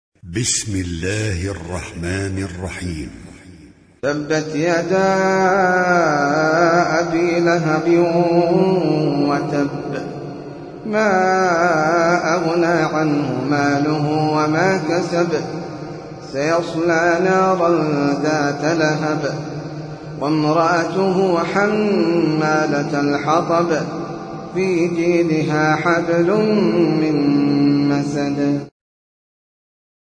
Sûrat Al-Masad (The Palm Fibre) - Al-Mus'haf Al-Murattal (Narrated by Hafs from 'Aasem)
high quality